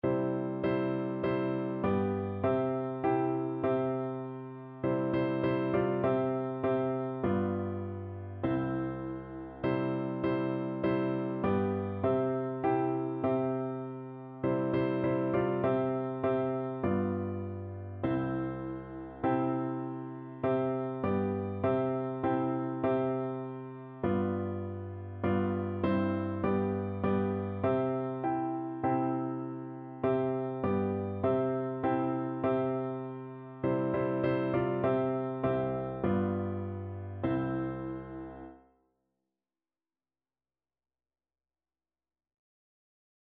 Christian Christian Piano Four Hands
Free Sheet music for Piano Four Hands (Piano Duet)
4/4 (View more 4/4 Music)
Classical (View more Classical Piano Duet Music)